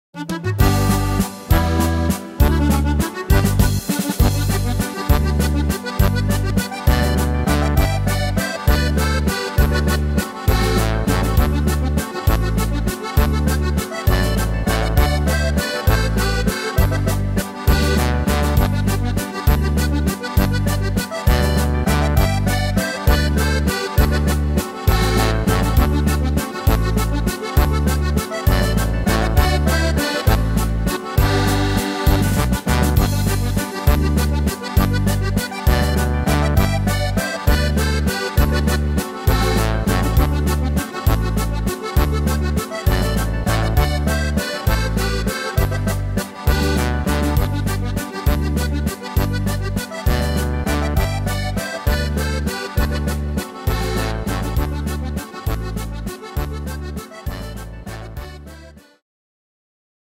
Tempo: 200 / Tonart: G -Dur